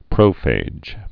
(prōfāj)